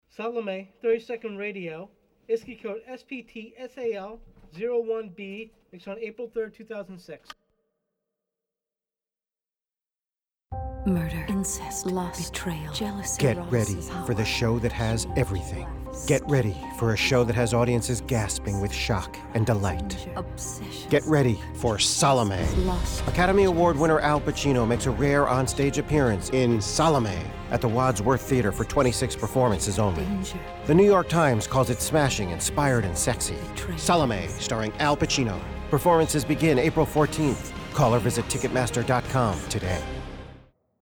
Рекламный радиоролик лос-анджелеской постановки пьесы-трагедии Оскара Уайлда "Саломея" можно прослушать и скачать по адресам:
SalomeRadioAd.mp3